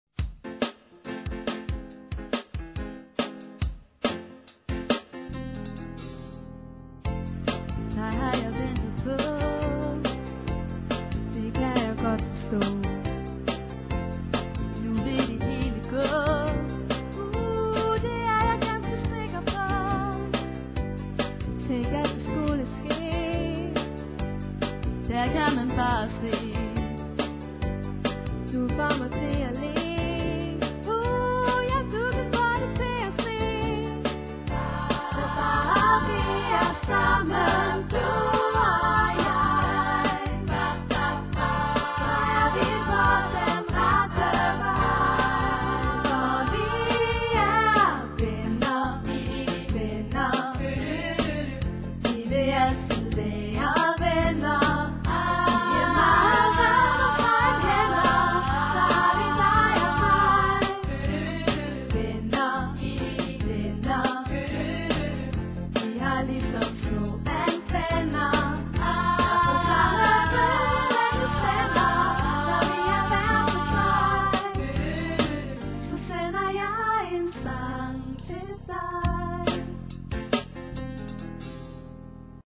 Derudover hører vi en stribe smukke og fængende ørehængere.